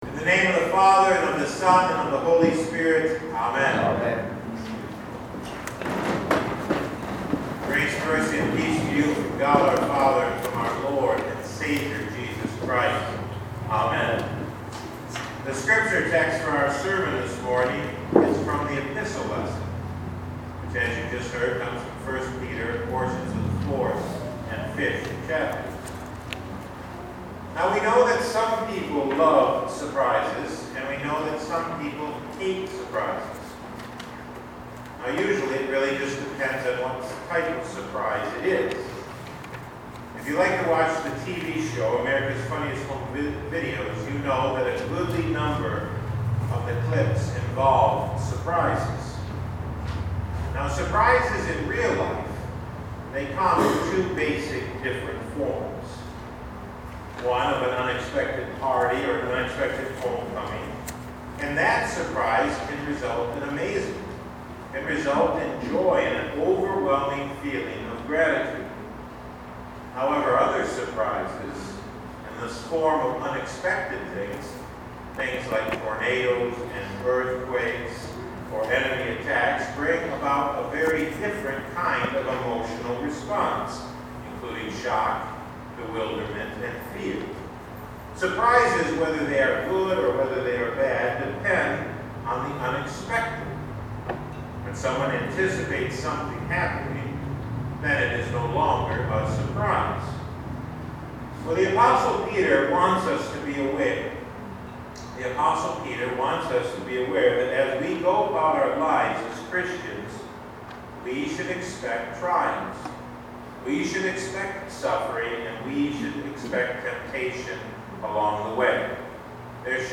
Past Sermons (Audio) - St. Luke Lutheran Church